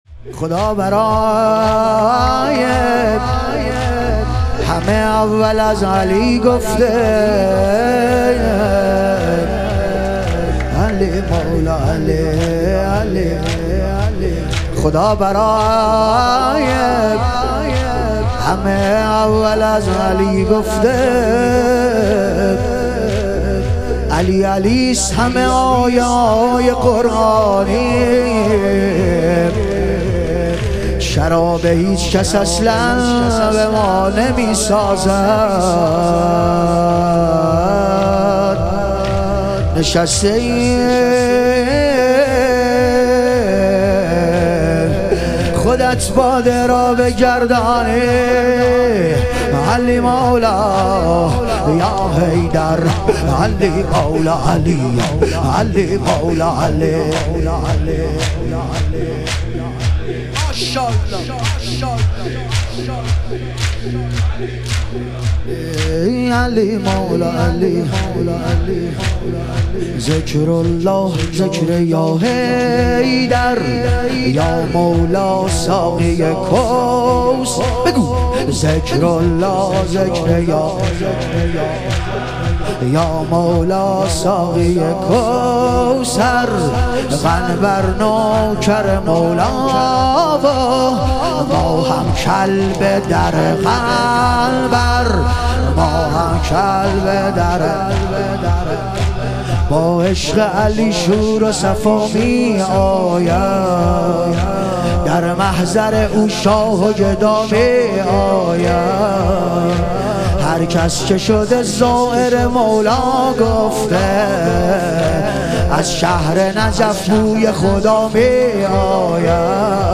شب شهادت حضرت جعفرطیار علیه السلام